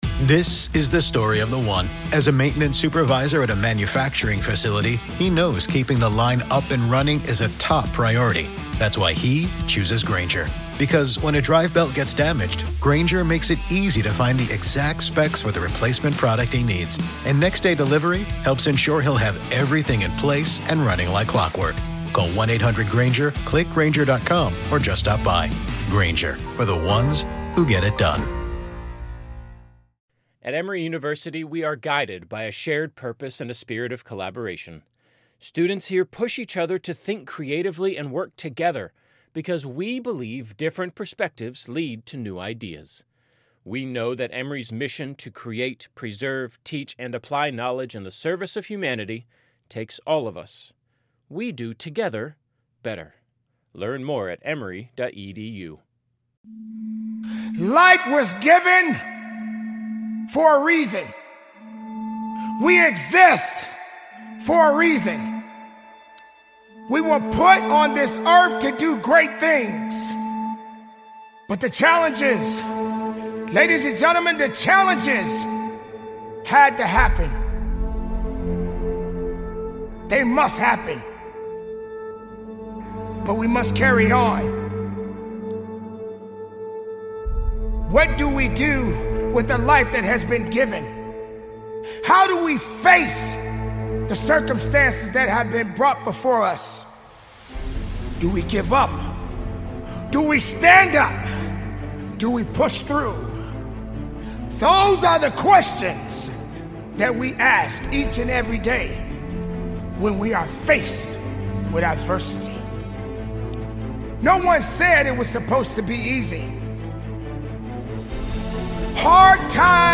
One of the Best Motivational Speeches